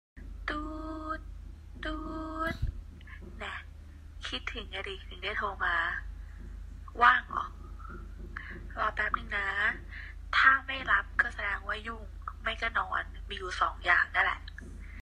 เสียงรอสายไลน์ ฟรี
เสียงรอสายไลน์ กวนๆ เสียงนาฬิกาปลุก ตื่นได้แล้วสายแล้ว
หมวดหมู่: เสียงเรียกเข้า